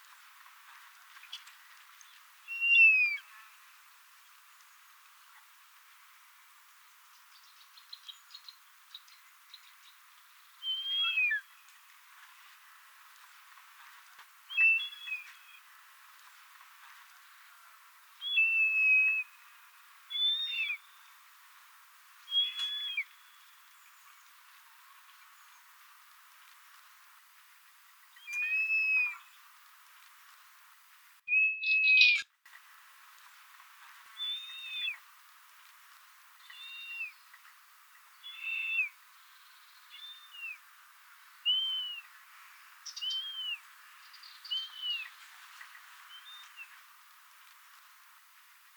E 11°20' - ALTITUDE: +340 m. - VOCALIZATION TYPE: flight calls.
- COMMENT: The bird was soaring in an updraft quite far from the recordist.